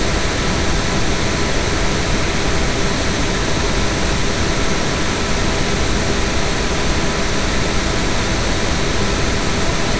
Pink Noise With Overtones And Other Crap
The background sound is very neat - it sounds like either a heavenly choir or a hairdryer. (Very relaxing..)